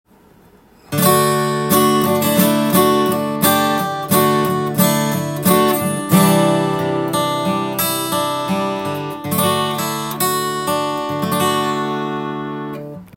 フレット交換後
帰ってきたギターを弾いてみました
音も違い過ぎてビックリです！
フレット交換後は、音に元気が宿っています。低音も高音も良く出ています。